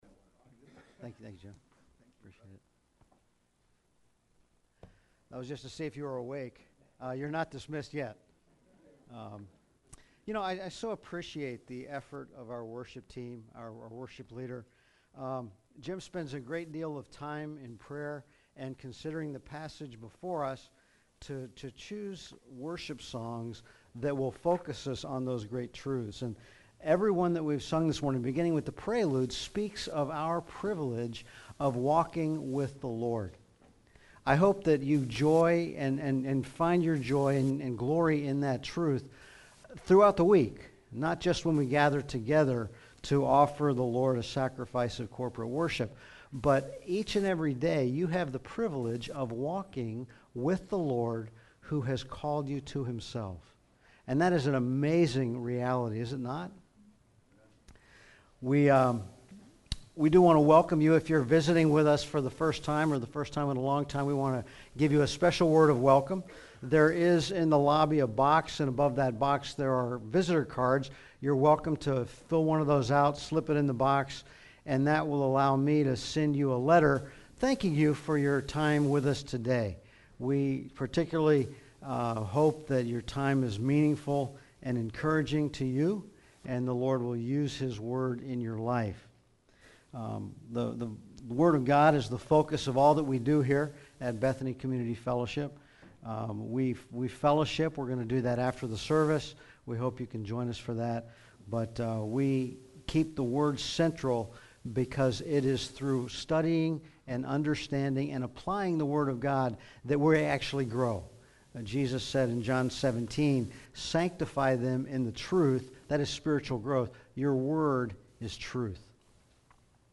An expository sermon on Malachi 3, a provocative escalation by God's people making accusation against the King.